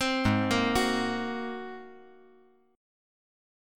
G#9 Chord
Listen to G#9 strummed